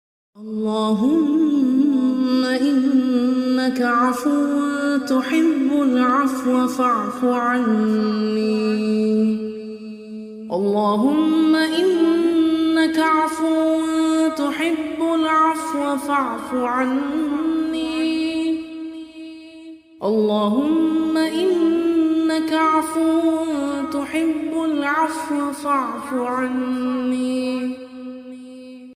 Audio Category:Zikr Hits:462 Date: | File Size:1MB Duration:1min Time:After Magrib Prayer Venue: Home